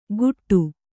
♪ guṭṭu